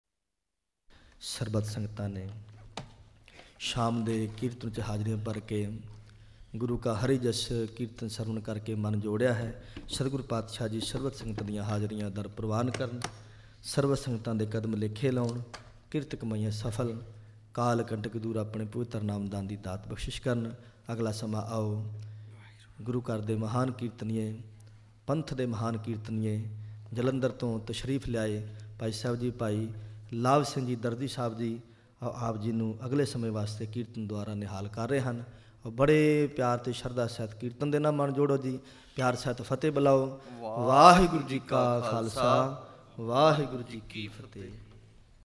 Album:Bridgewater, New Jersey - 2005-05-27 - Evening
Genre: Shabad Gurbani Kirtan